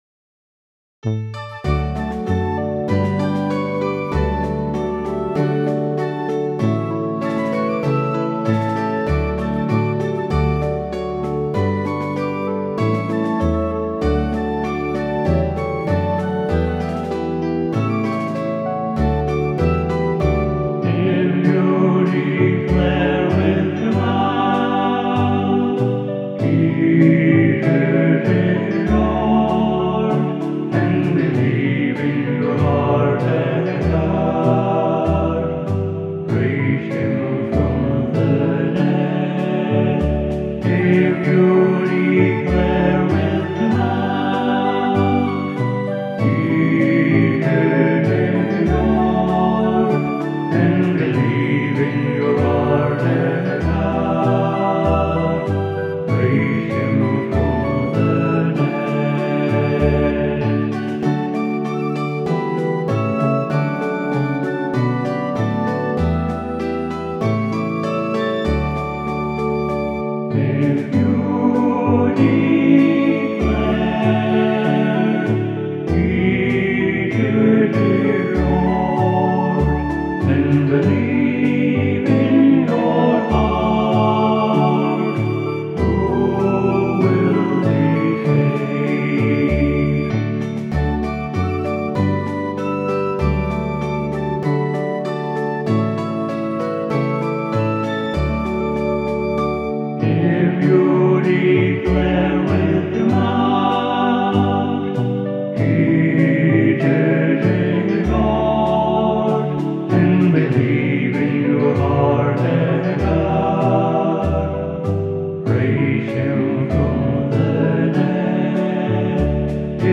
voice only